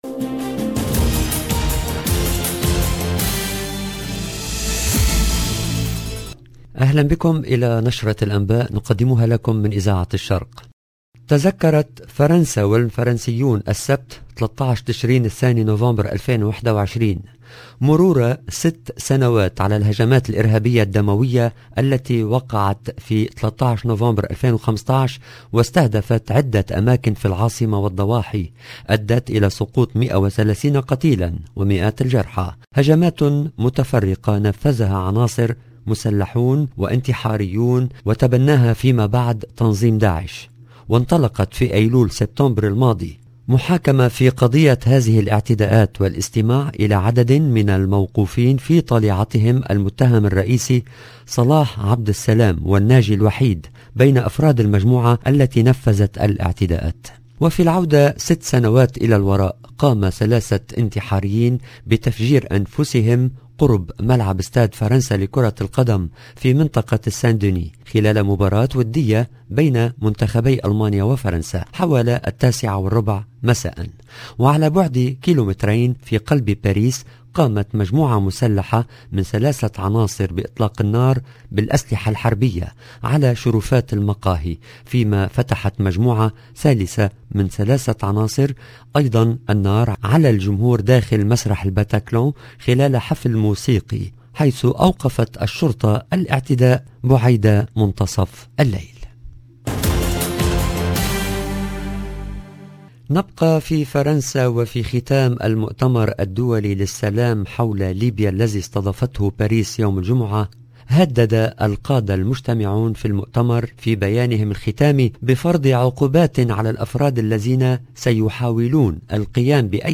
LE JOURNAL EN LANGUE ARABE DU SOIR DU 13/11/21